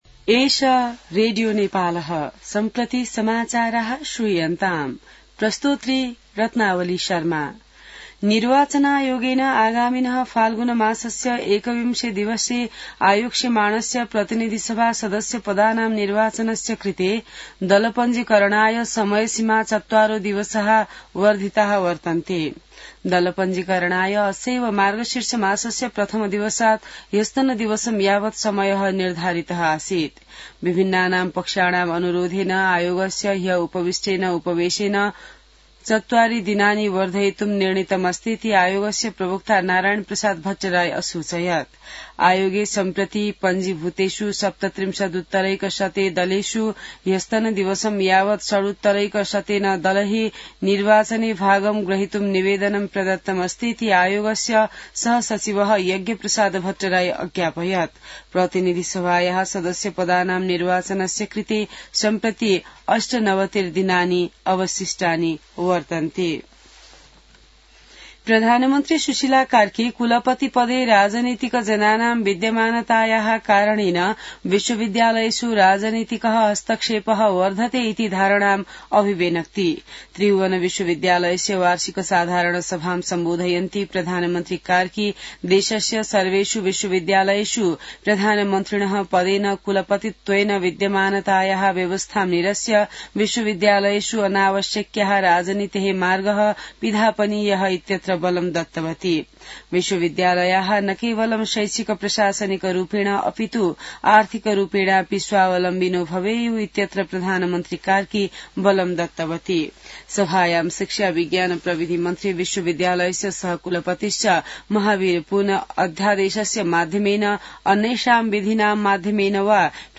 An online outlet of Nepal's national radio broadcaster
संस्कृत समाचार : ११ मंसिर , २०८२